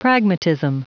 Prononciation du mot pragmatism en anglais (fichier audio)